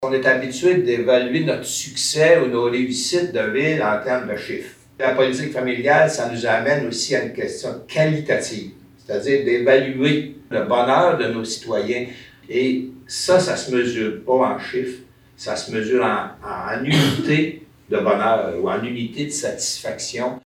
Le maire de Bécancour, Jean-Guy Dubois, explique que cette politique ramène la conversation à un sujet souvent oublié derrière les chiffres.